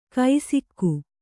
♪ kai sikku